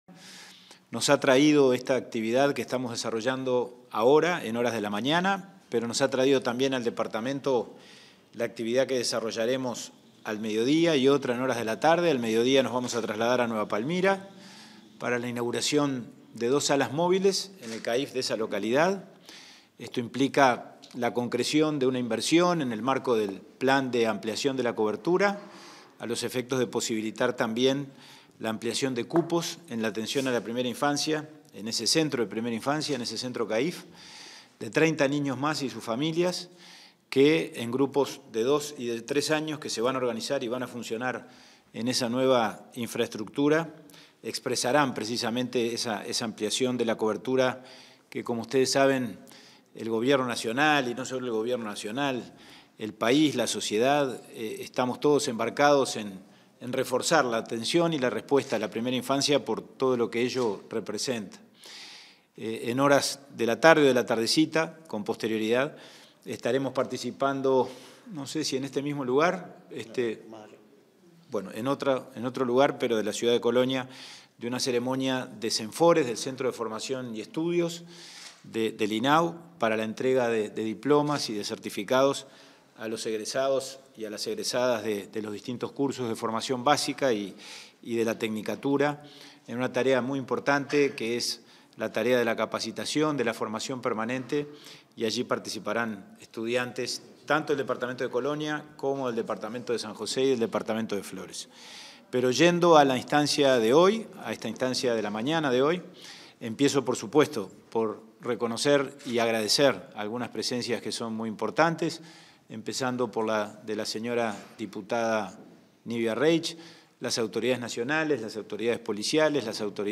Palabras del presidente del INAU, Pablo Abdala